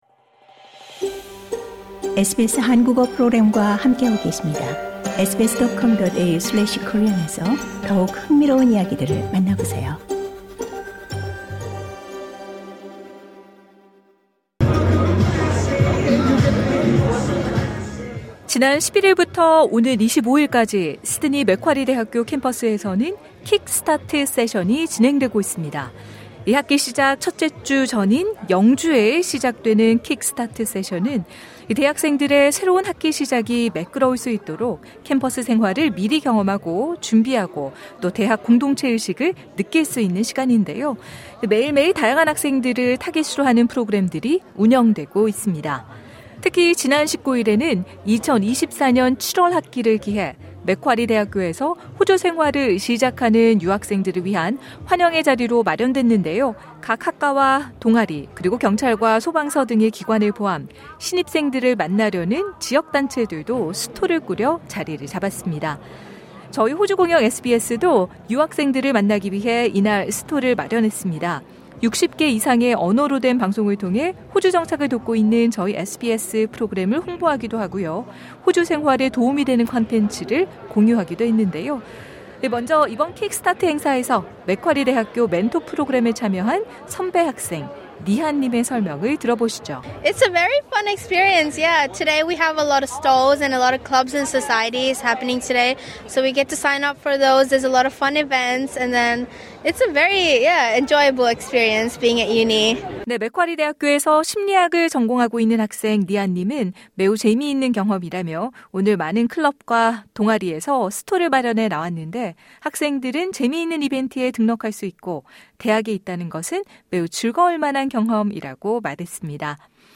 SBS 한국어 프로그램이 지난 19일 맥쿼리 대학교에서 실시된 킥스타트 세션에 참석해 새롭게 호주 유학을 시작하는 한국 학생들과 맥쿼리 대학 재학생들을 만났다.